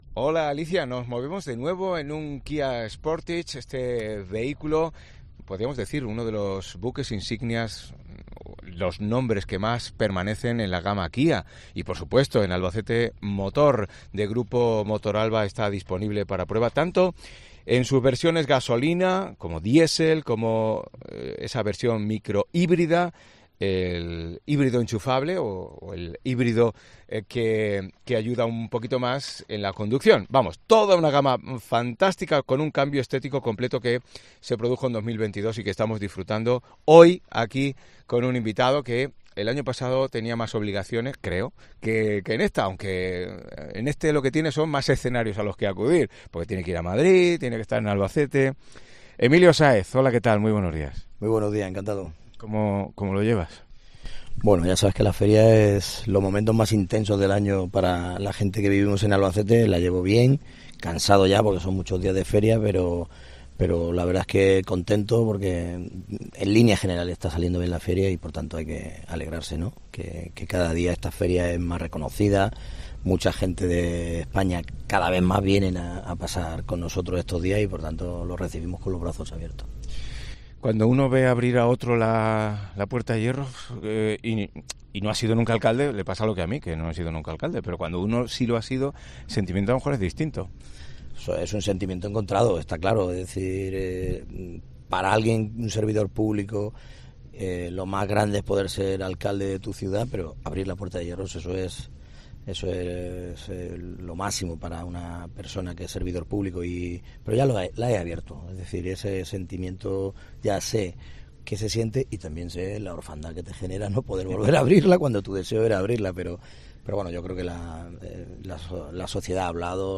AUDIO: El exalcalde y diputado por el PSOE nos habla de la Feria, de la ciudad y de la situación en España desde un Kia Sportage de Albacete Motor